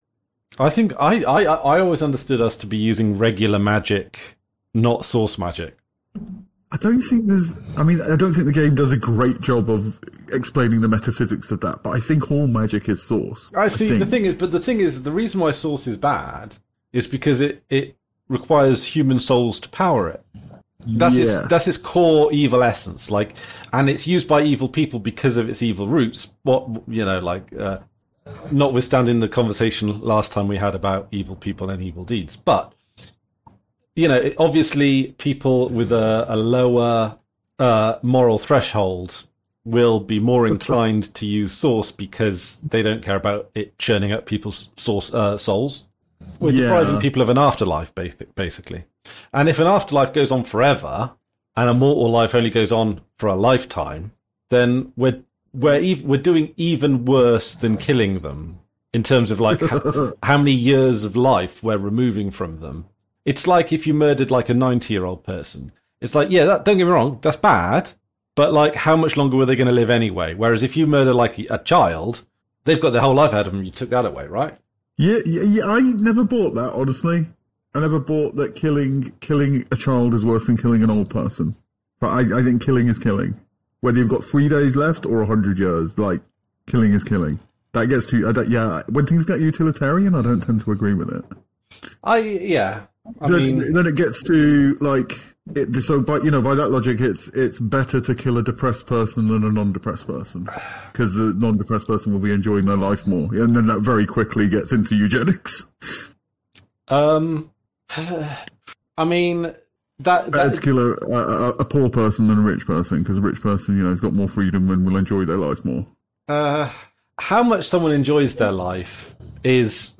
Livestream discussion blending gaming and philosophy. We start by exploring the lore and morality of Divinity: Original Sin, then dive deep into questions about ethics, age, and the value of life, from trolley problems to utilitarian dilemmas.